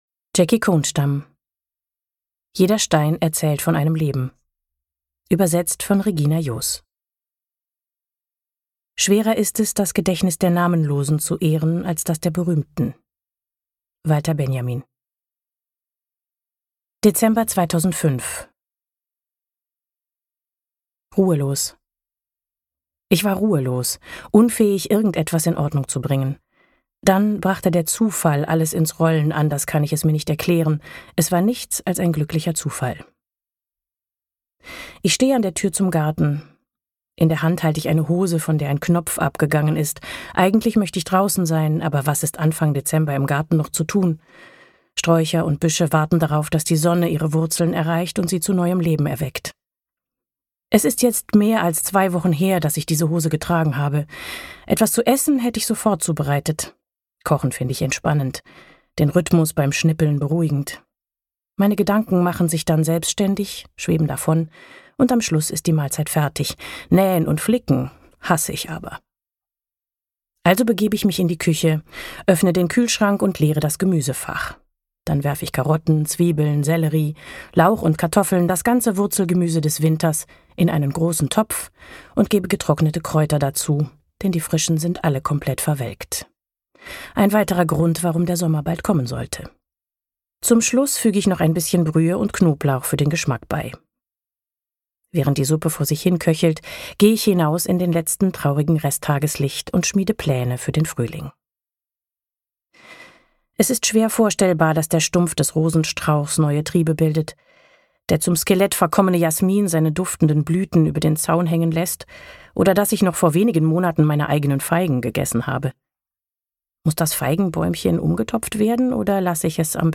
Hörbuch: Jeder Stein erzählt von einem Leben.